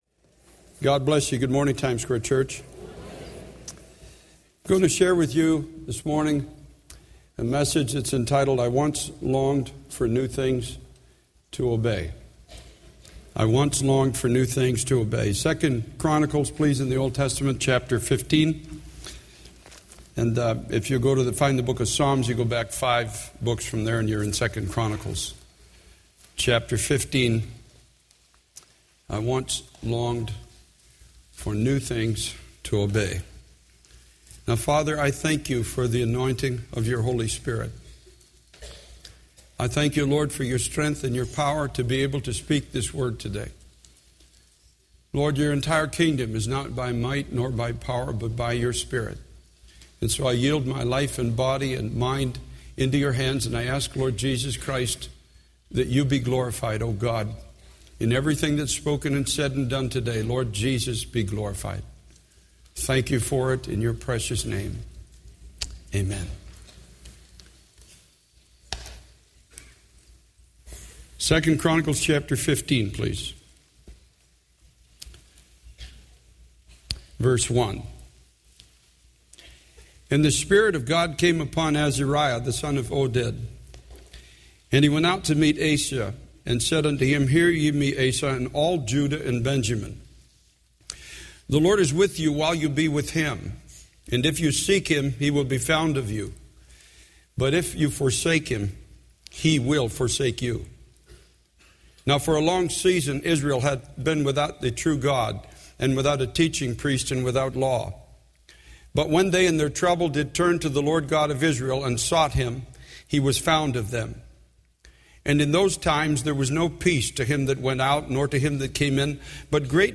This sermon emphasizes the importance of renewing the altar of the Lord in our lives, shunning evil, embracing good, and maintaining a servant's heart. It highlights the dangers of turning from the spirit to the flesh, losing the fear of God, and locking away the voice of correction. The speaker urges the congregation to return to a place of genuine worship, obedience, and passion for God, seeking to finish the race well and hear the voice of God clearly.